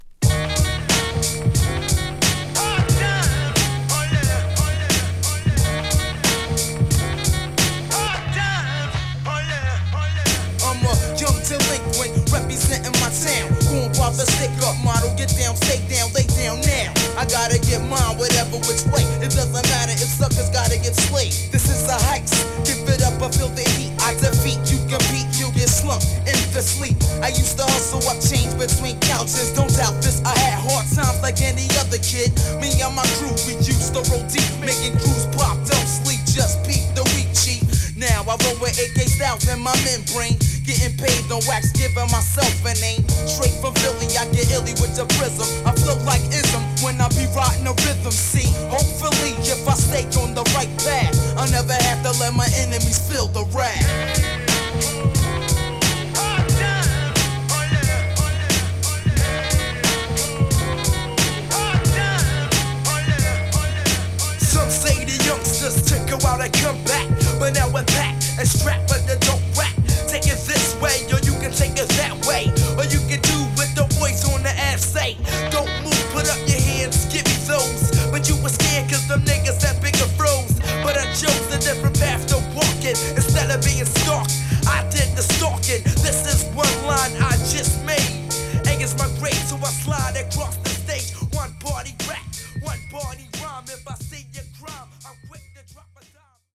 2. > HIPHOP